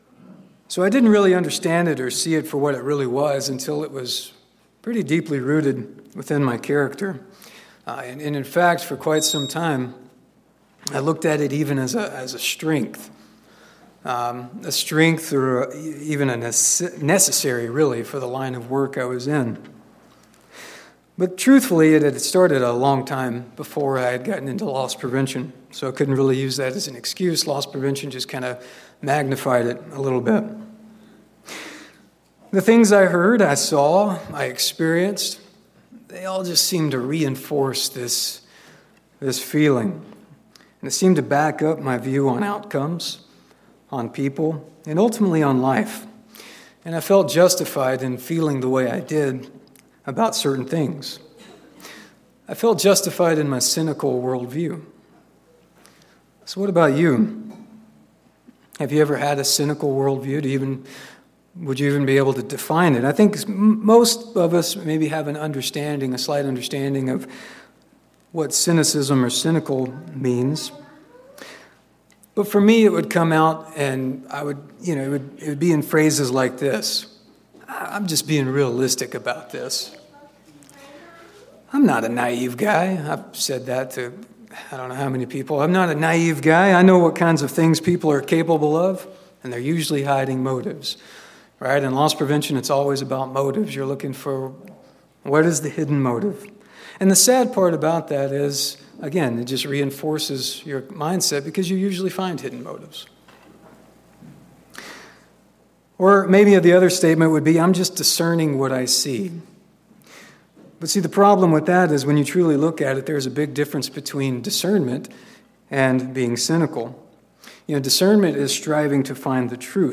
Sermons
Given in Spokane, WA Kennewick, WA Chewelah, WA